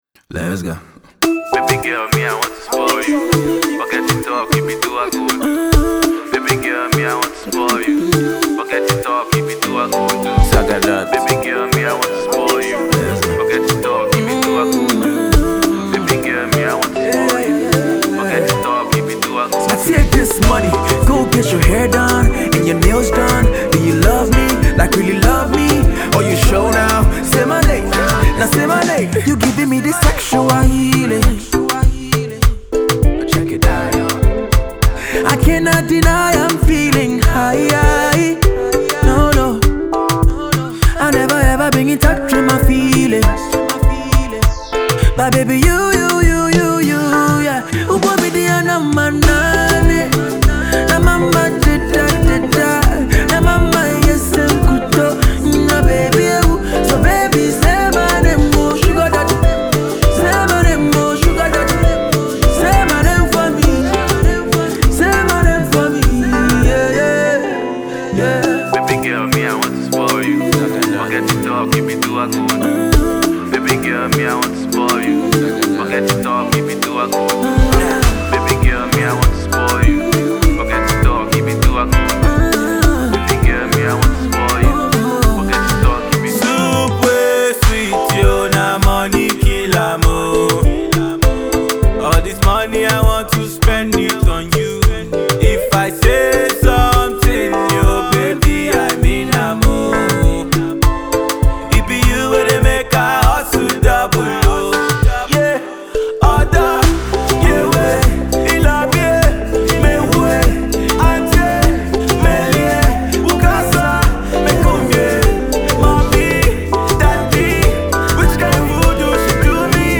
groovy record